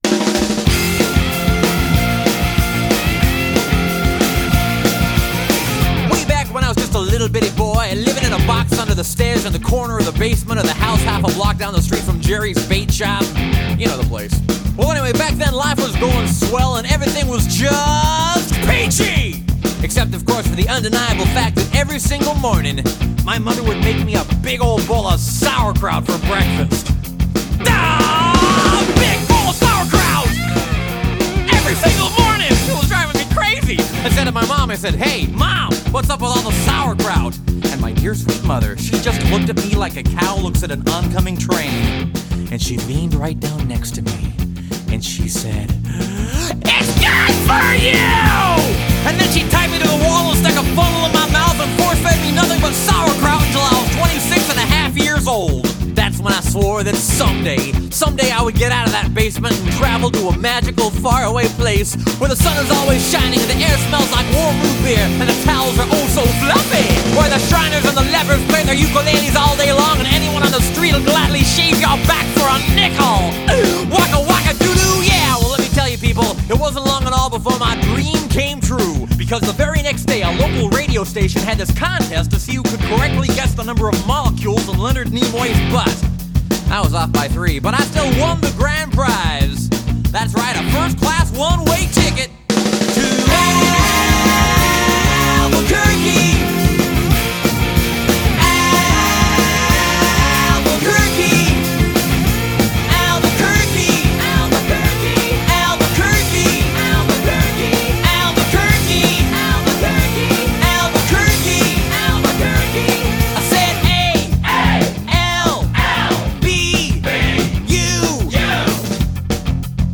BPM187
Audio QualityMusic Cut